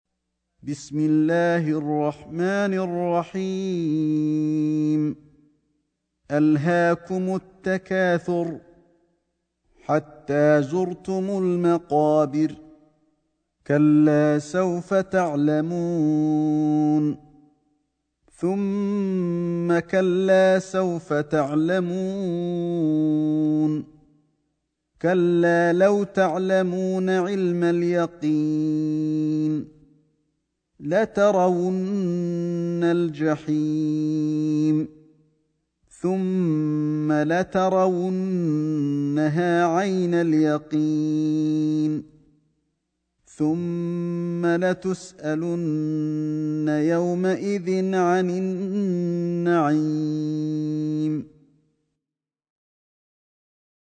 سورة التكاثر > مصحف الشيخ علي الحذيفي ( رواية شعبة عن عاصم ) > المصحف - تلاوات الحرمين